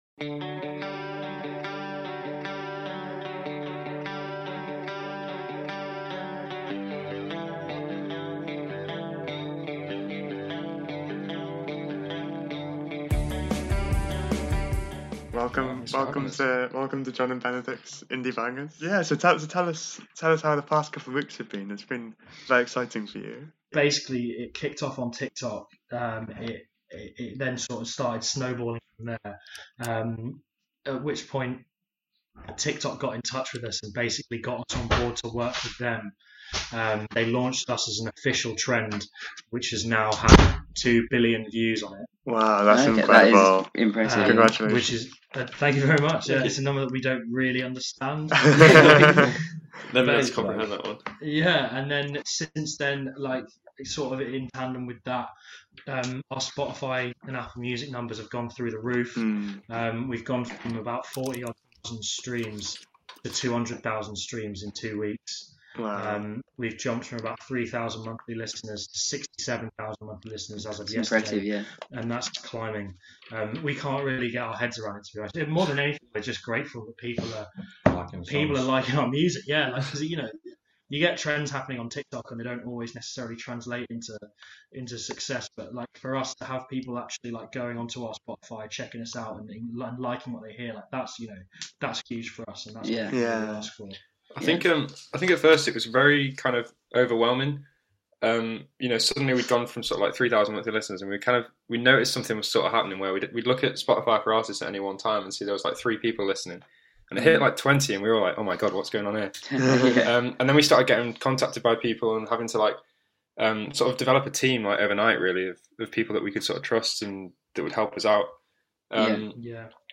Specialist Indie music presenters